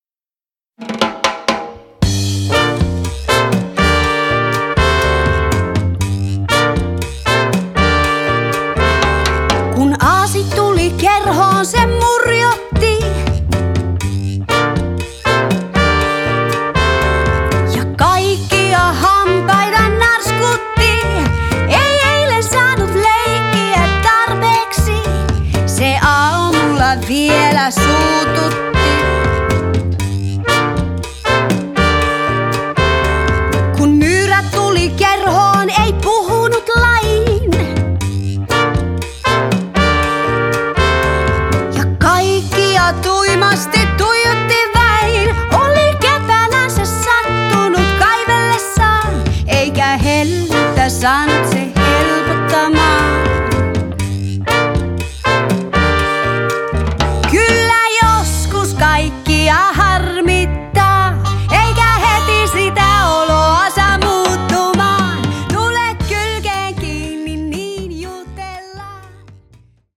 cha cha